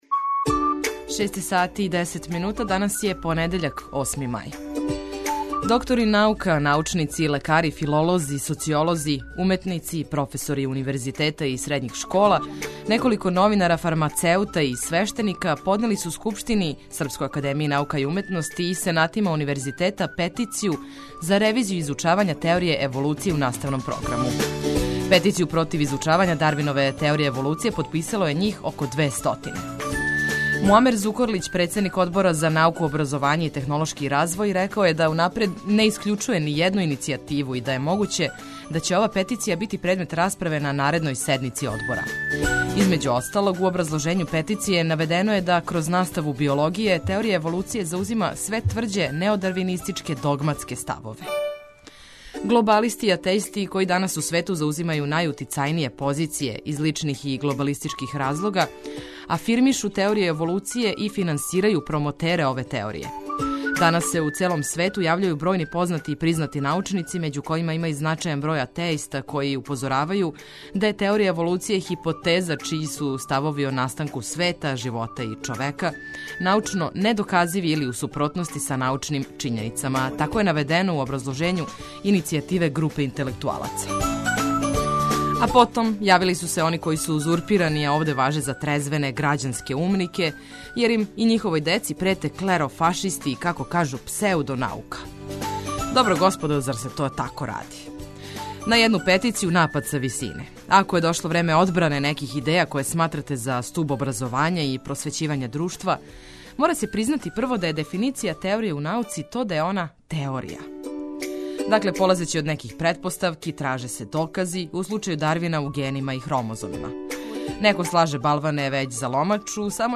Нови дан дочекујемо уз осмех, много корисних и важних информација, а јутро улепшавамо и музиком уз коју ћете сигурно лакше да се разбудите, певушећи у сусрет свим обавезама.